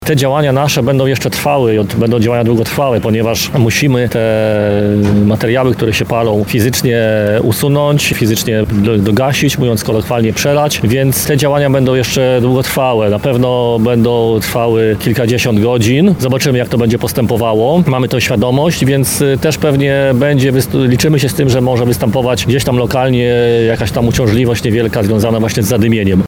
– mówi st. bryg. Zenon Pisiewicz, Komendant Wojewódzki Państwowej Straży Pożarnej w Lublinie.